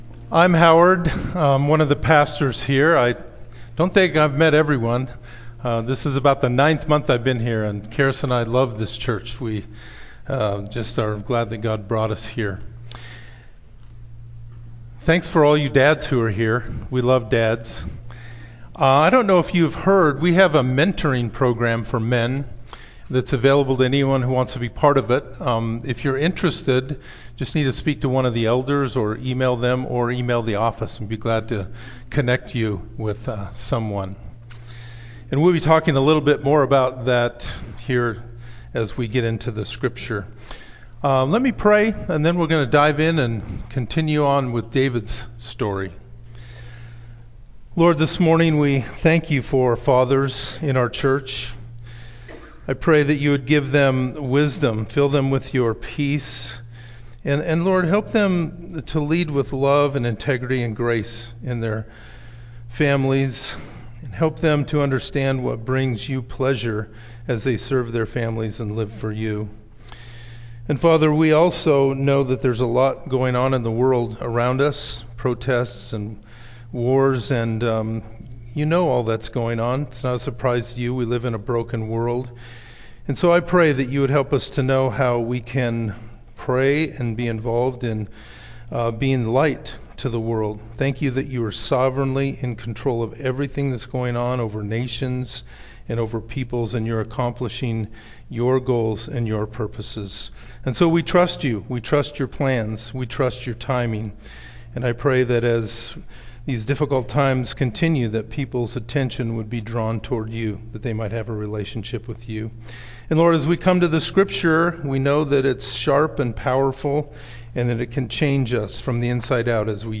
Related Topics: sermon